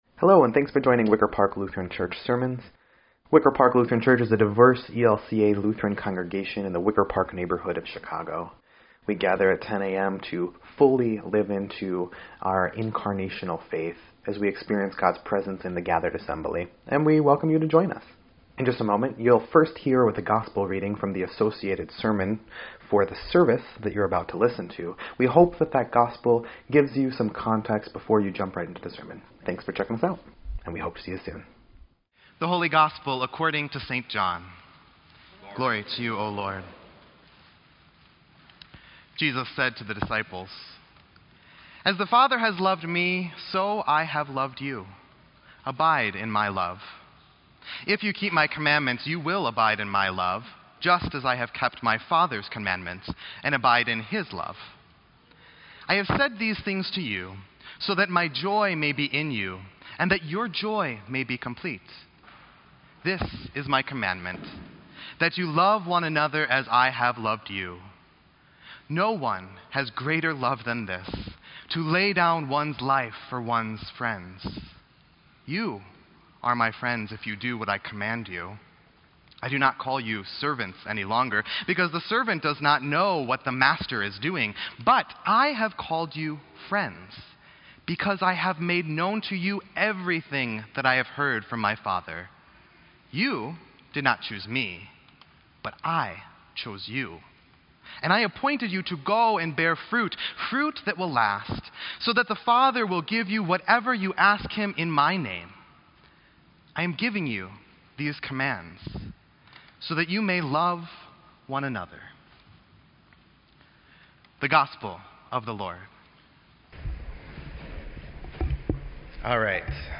Sermon_5_6_18_EDIT.mp3